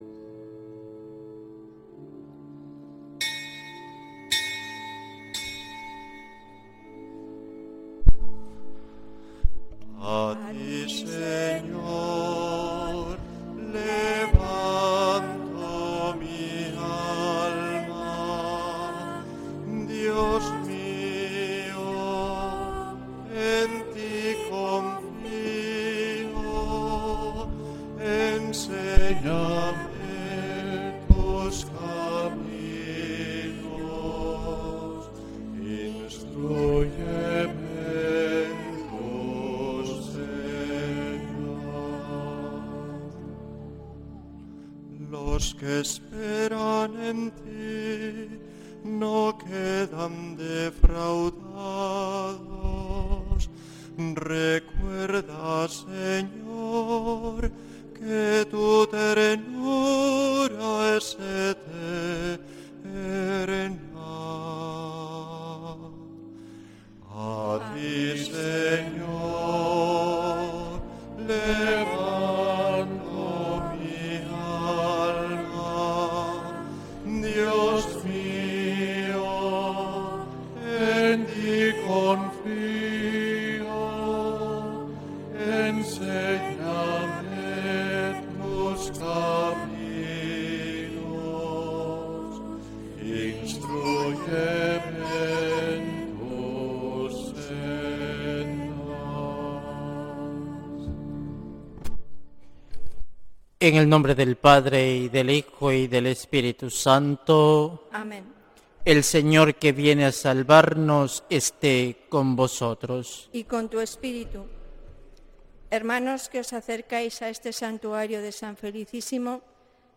Santa Misa desde San Felicísimo en Deusto, domingo 30 de noviembre de 2025